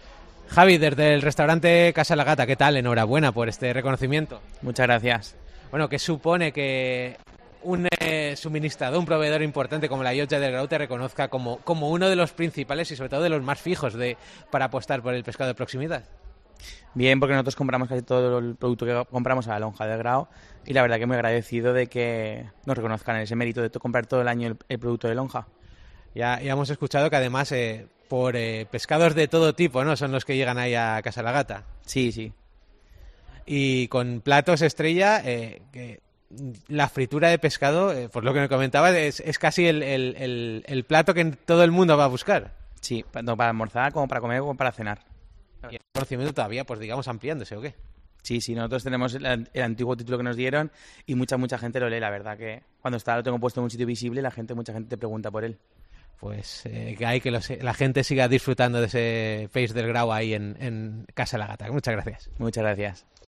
Un total de 10 establecimientos de los que vamos a escuchar sus palabras tras recibir este galardón.